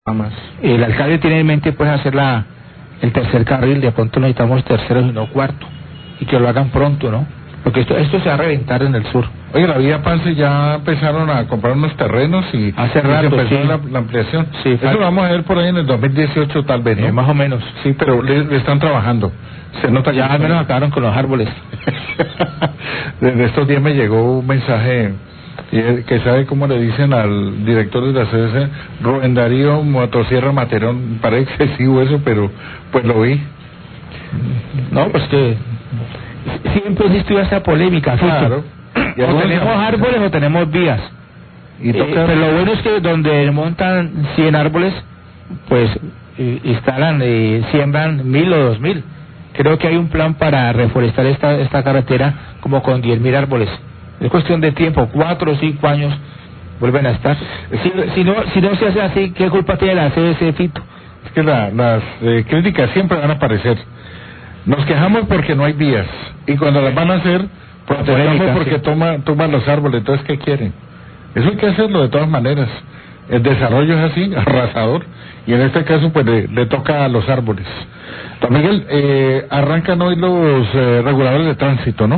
Radio
Periodista comentan que al director de la CVC le dicen en redes sociales Rubén Dario "Motosierra" Materón debido  a la autorización para la tala de árboles por obras de ampliación en la vía a Pance. Pero a renglón seguido aseguran que es un comentario excesivo y que siempre habrán criticas exageradas por hechos similares debido a obras de desarrollo.